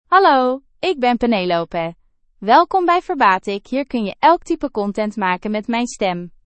Penelope — Female Dutch AI voice
Penelope is a female AI voice for Dutch (Netherlands).
Voice sample
Female
Penelope delivers clear pronunciation with authentic Netherlands Dutch intonation, making your content sound professionally produced.